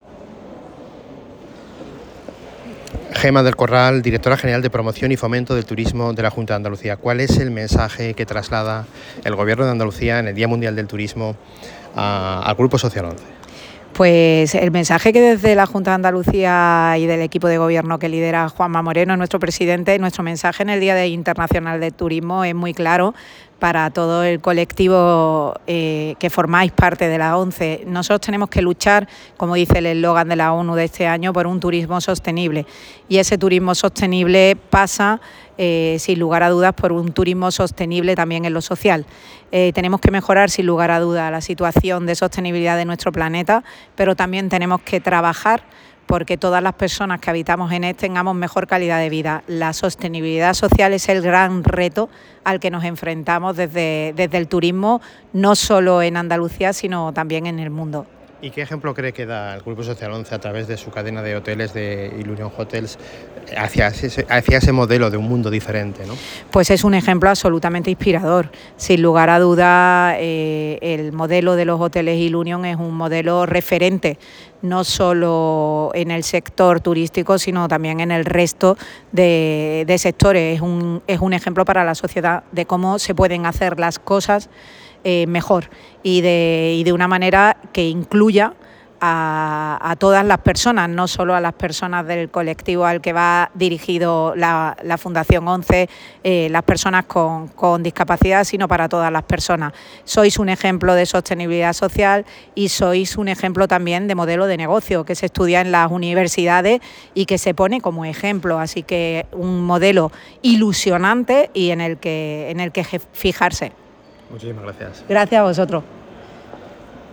Presentación del cupón del Día del Turismo en el Hotel Ilunion Alcora Sevilla
Intervención de Gemma del Corral
Declaraciones de Gemma del Corral